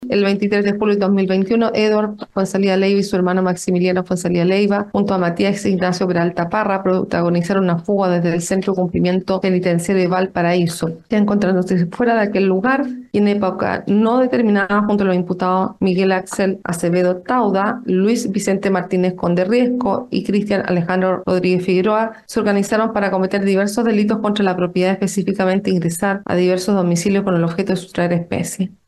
La magistrada del Juzgado de Garantía de Quilpué, Daniela Rodríguez, en medio de la exposición de los hechos que imputa la fiscalía, detalló que tres de los involucrados se fugaron del Centro del Cumplimiento Penitenciario de Valparaíso.
jueza-fuga.mp3